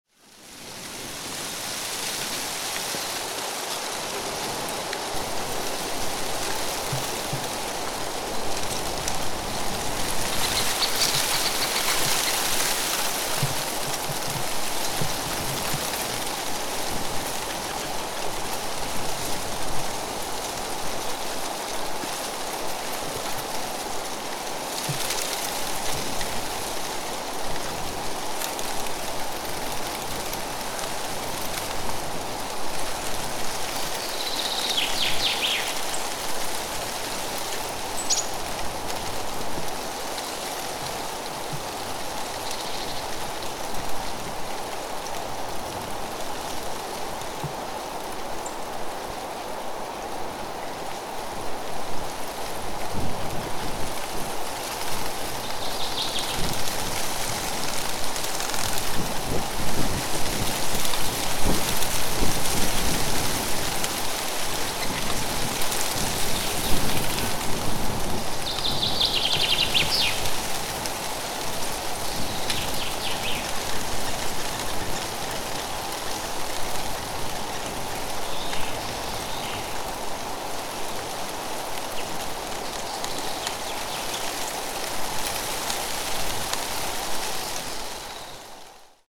Leaves Blowing: A Soothing Nature Sound Effect
Leaves Blowing In The Wind Sound Effect
Gentle breeze through leaves in a forest.
Genres: Sound Effects
Leaves-blowing-in-the-wind-sound-effect.mp3